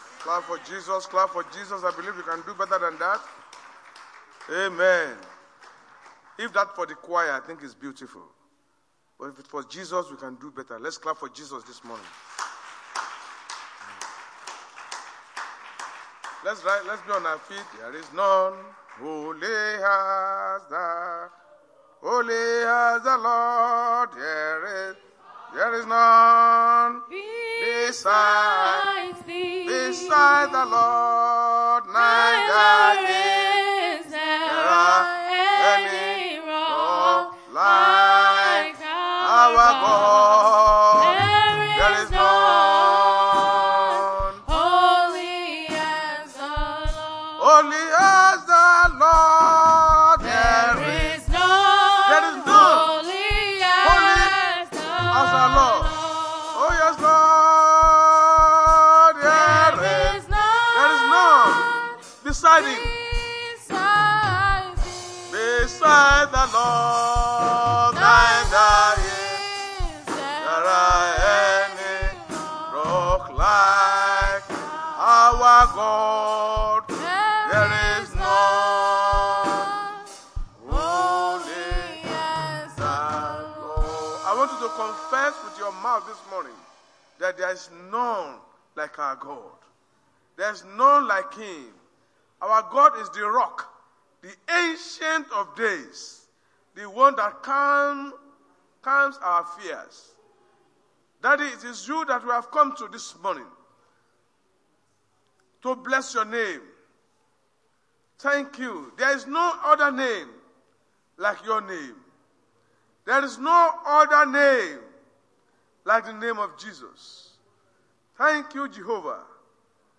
RCCG House Of Glory Sunday Sermon: Calm Your Fears
Service Type: Sunday Church Service